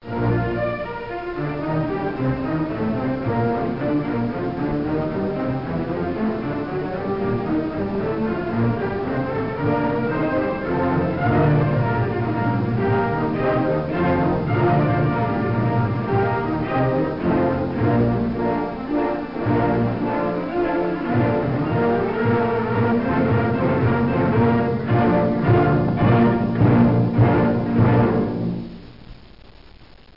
Nel 1935 il gruppo tedesco BASF/AEG mostrò al pubblico il primo registratore a nastro (in figura) di cui potete anche ascoltare un
La velocità del nastro, che in questo modello era di ben 760 cm/sec, diminuì gradualmente fino ai 72 cm/sec. degli anni '50, ai 38 cm/sec. delle registrazioni professionali degli anni '70, mentre i modelli amatoriali avevano velocità di 19 e 9.5 cm/sec.